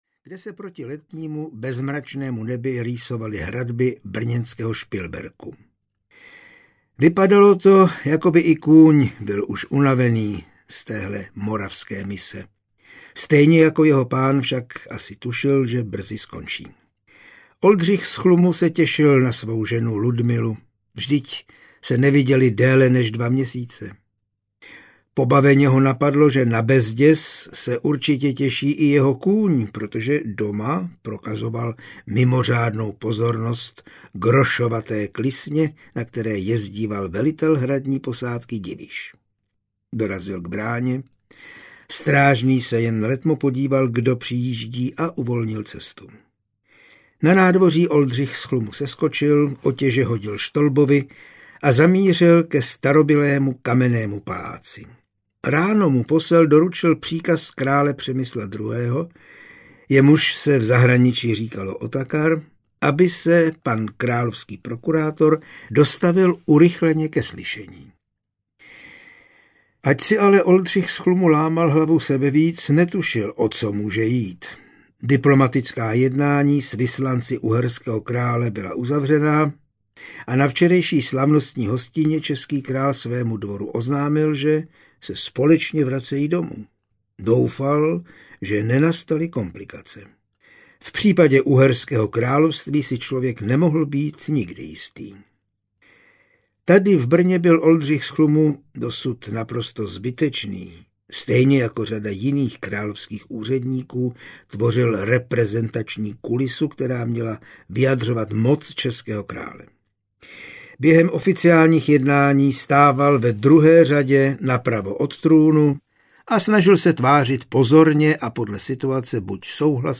Duch znojemských katakomb audiokniha
Ukázka z knihy
duch-znojemskych-katakomb-audiokniha